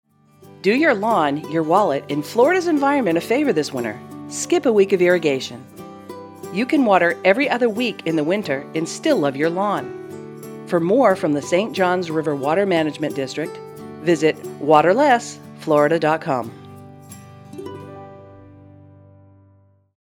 Public service announcements
Skip a Week--PSA 1 20sec.mp3